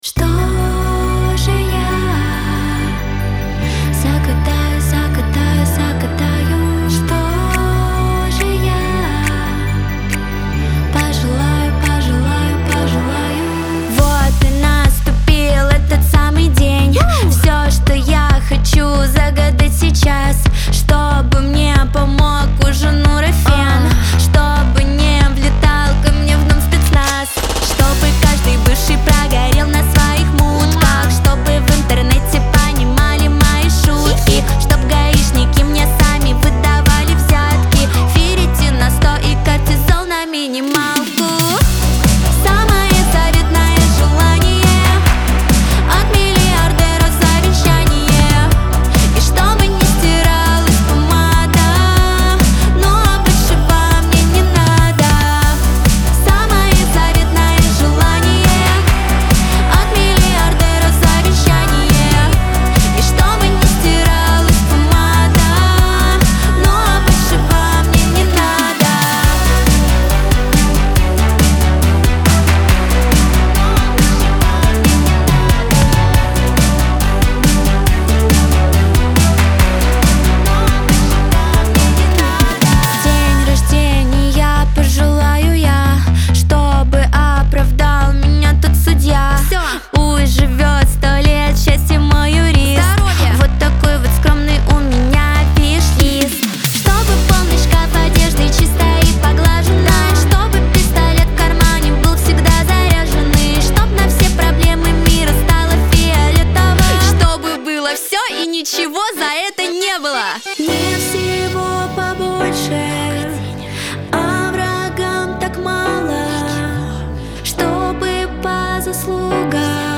pop
диско , эстрада , Веселая музыка